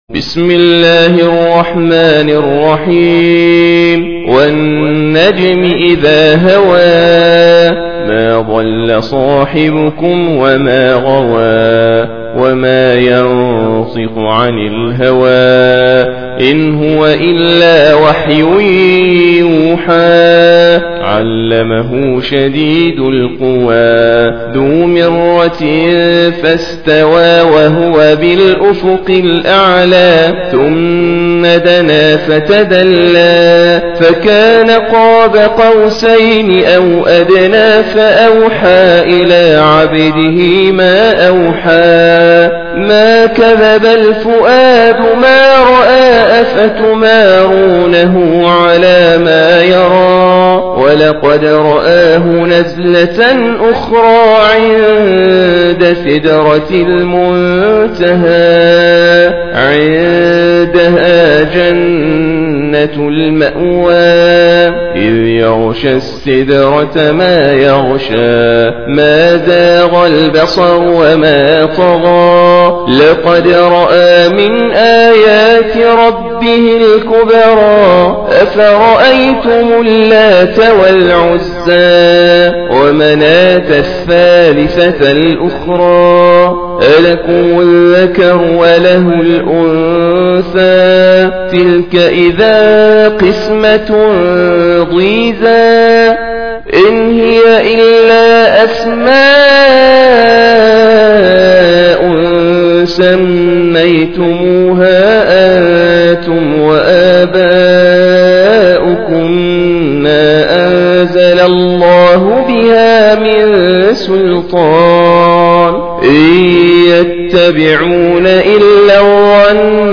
53. Surah An-Najm سورة النجم Audio Quran Tarteel Recitation